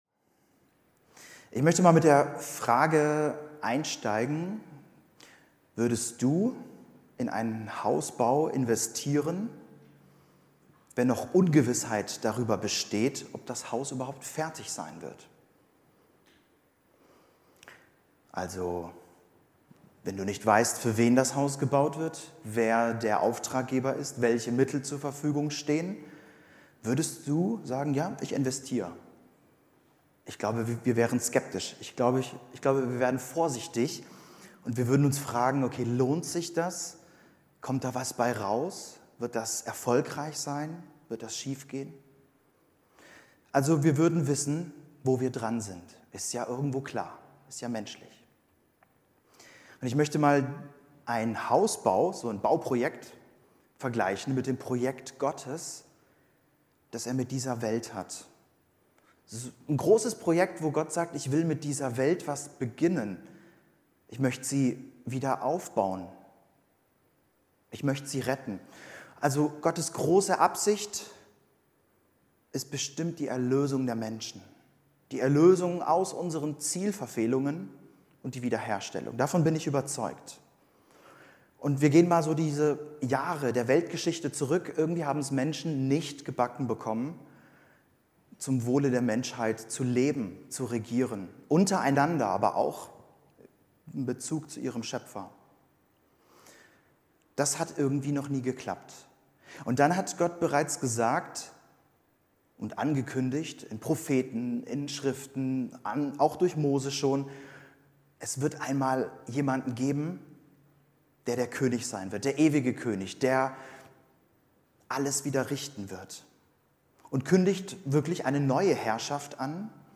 Predigten über den christlichen Glauben und Leben